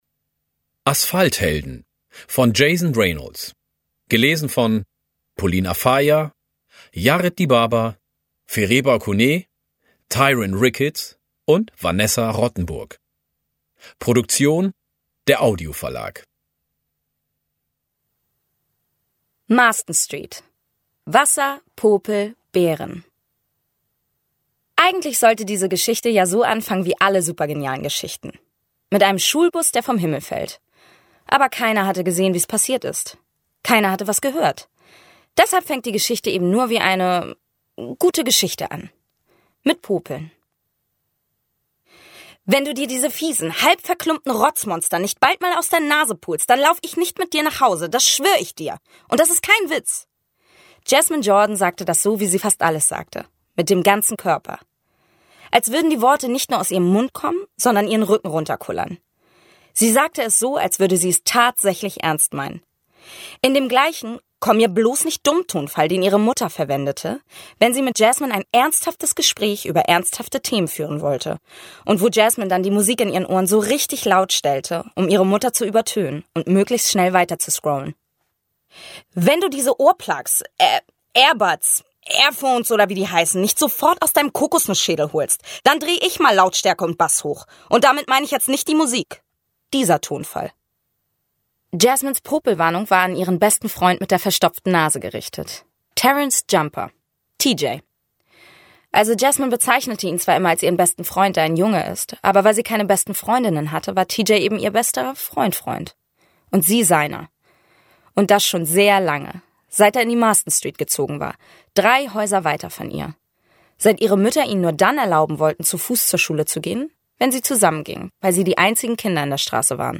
Asphalthelden Ungekürzte Lesung